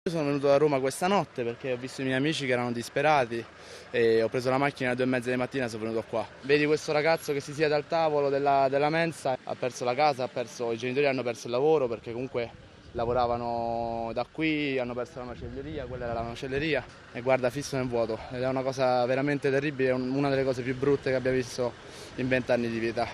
Anche un giovane residente a Roma si è recato a Pescara del Tronto: